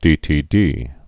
(dētē-dē)